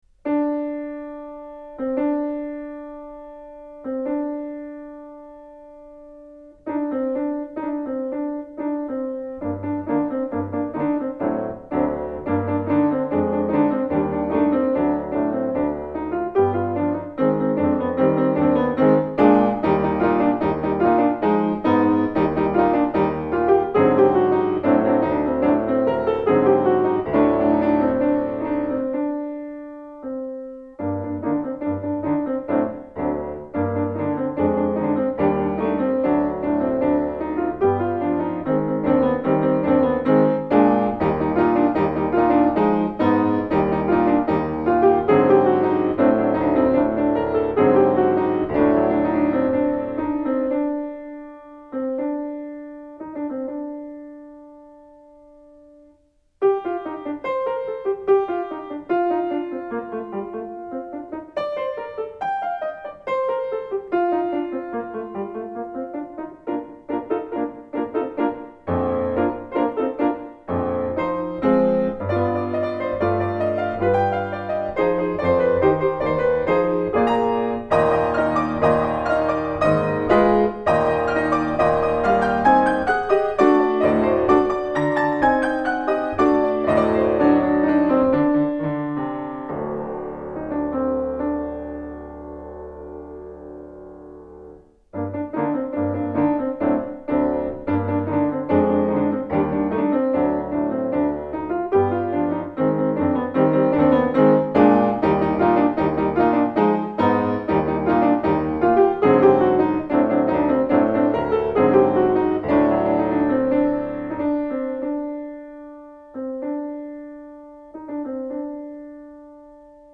La mia più grande soddisfazione quando torno a casa è mettermi al pianoforte e suonare qualche pezzo che debbo perfezionare (cioè tutti quelli belli... perché sono i più difficili).
"Zambra" di Enrique Granados: ha dei dolci contrasti astratti.